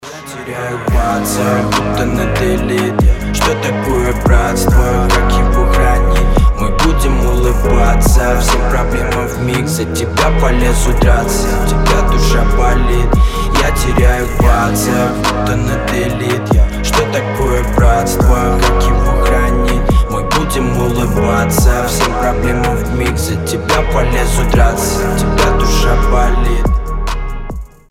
• Качество: 320, Stereo
лирика
душевные
грустные
русский рэп